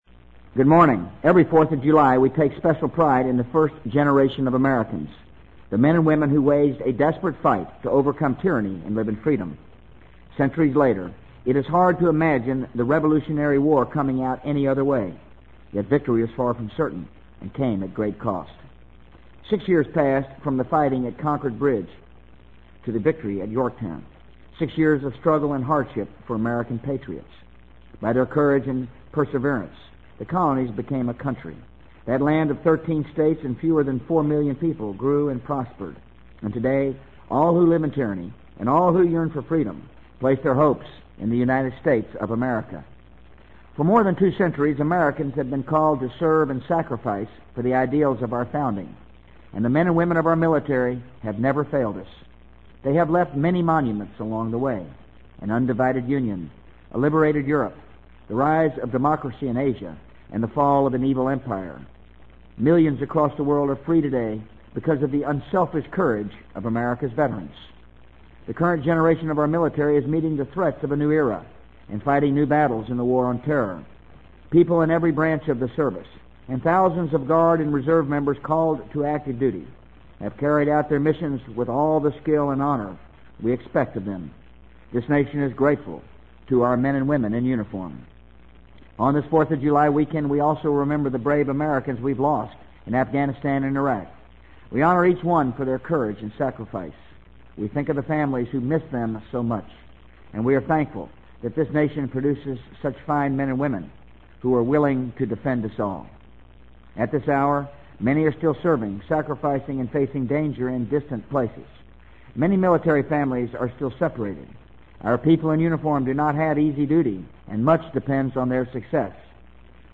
【美国总统George W. Bush电台演讲】2003-07-05 听力文件下载—在线英语听力室